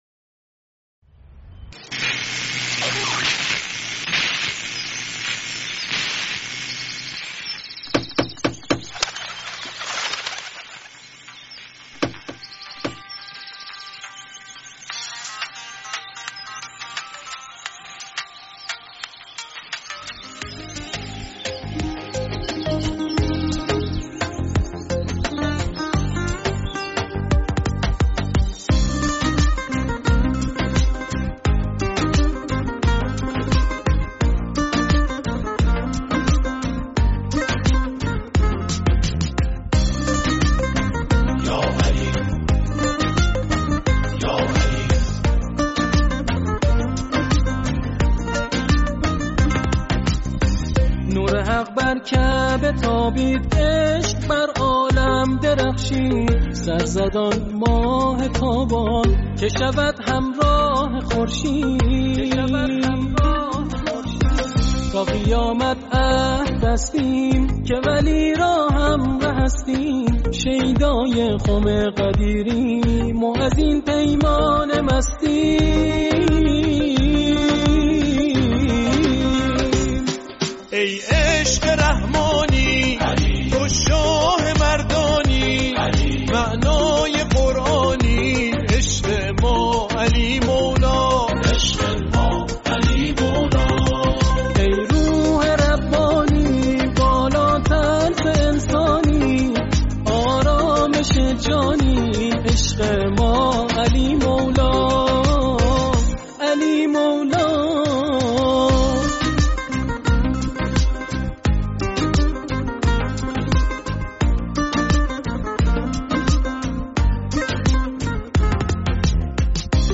شیوه اجرا: اركستر || فرم: ترانه
ترانه ی پاپ با مضمون عید غدیر